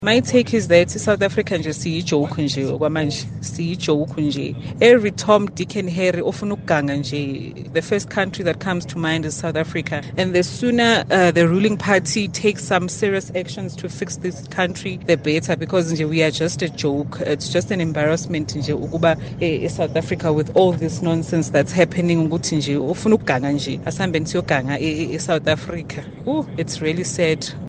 However, as was evident by listener’s views on Kaya Drive, not many share the President’s sentiments.